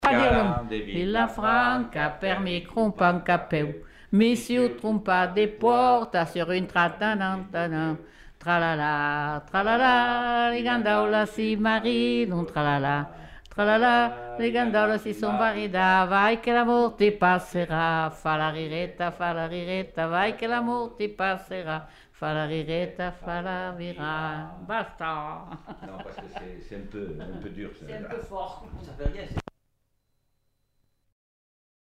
Aire culturelle : Provence
Lieu : Coaraze
Genre : chant
Effectif : 2
Type de voix : voix d'homme ; voix de femme
Production du son : chanté
Description de l'item : fragment ; 1 c. ; refr.